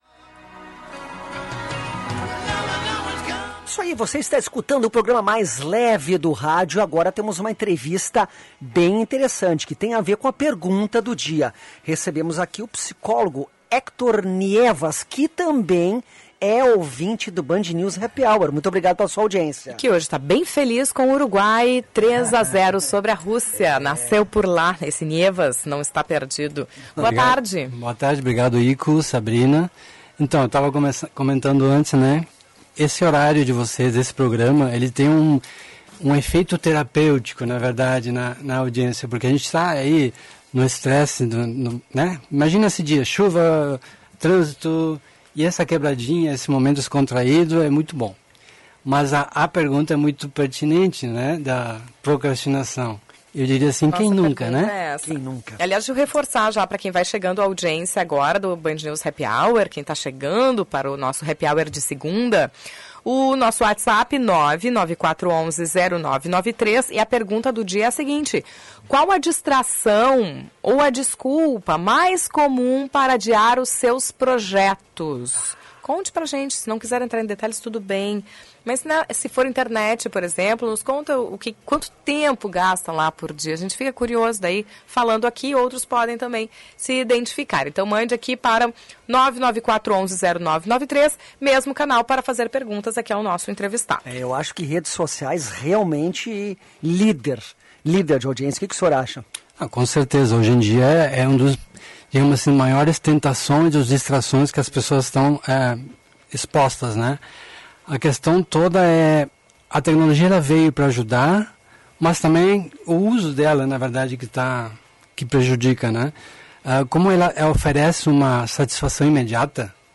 Ouça o trecho da entrevista do dia 25/jun/2018.